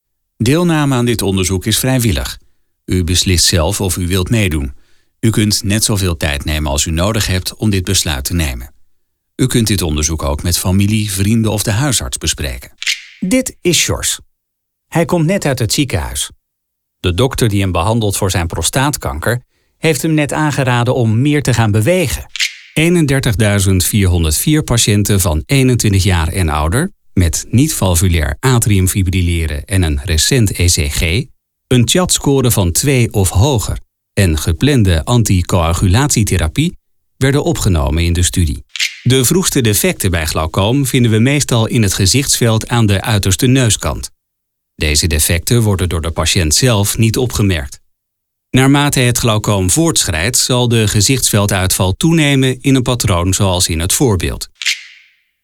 Met mijn jarenlange ervaring als stemacteur, gecombineerd met hoogwaardige opnameapparatuur in mijn geïsoleerde spreekcel, lever ik opnames die direct klaar zijn voor gebruik in jouw productie.
Luister ook even naar deze demo's:
Medisch